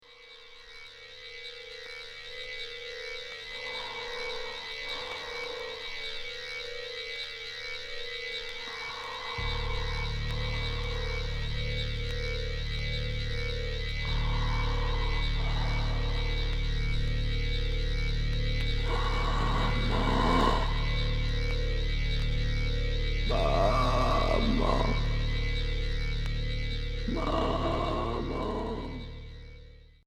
Expérimental Unique 45t